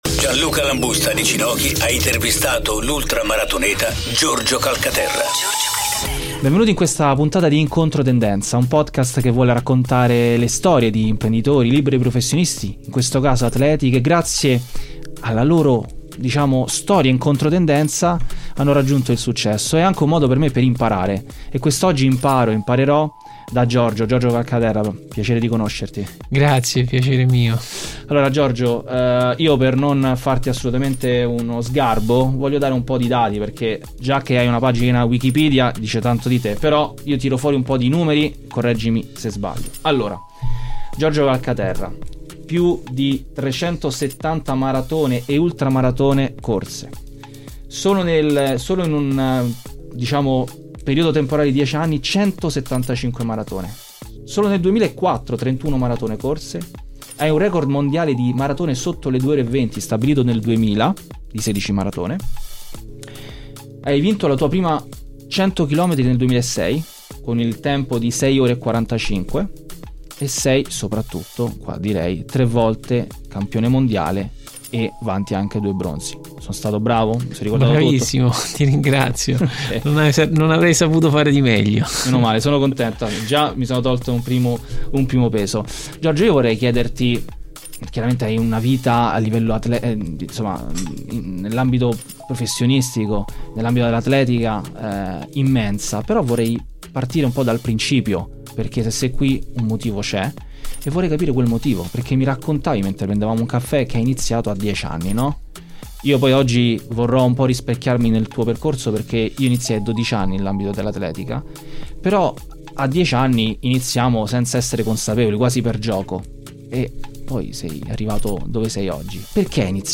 Intervista a Giorgio Calcaterra (ep. 1 di 2) - Radio Globo